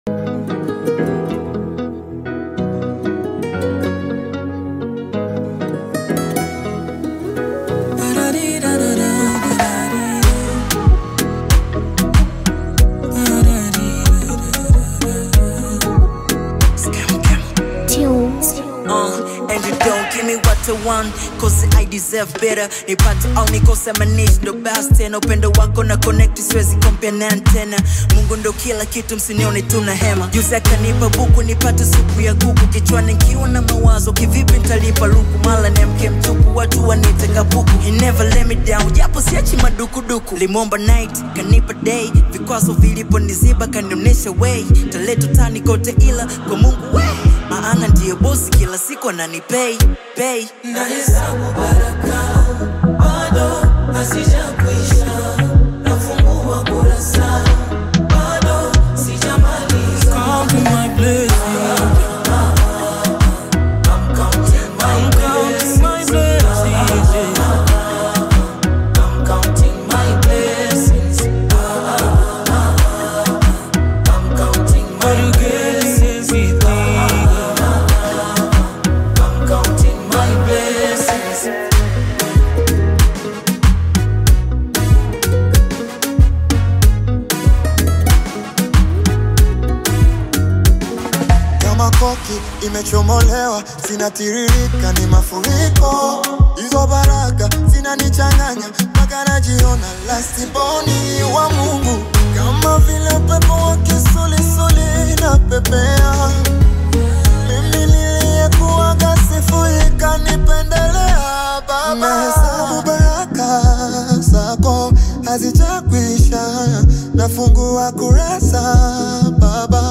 Through heartfelt lyrics and emotional delivery